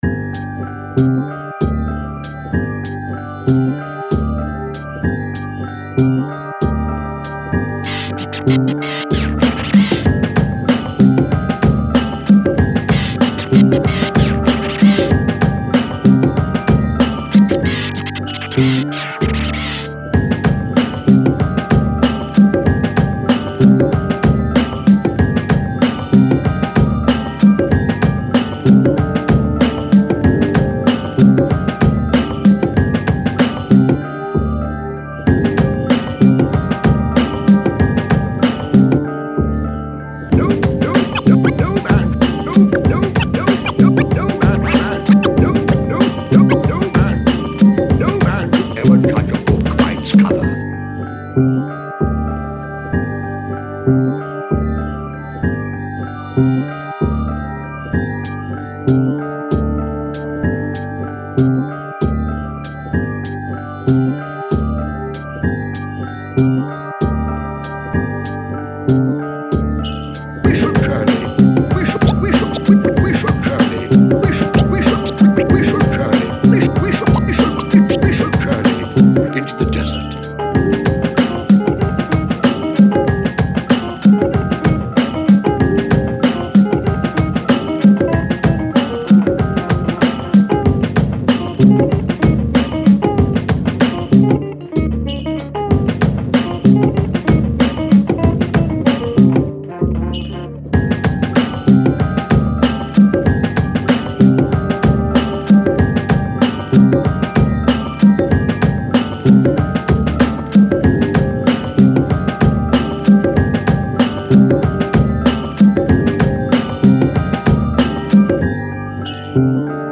abstract hip-hop, trip-hop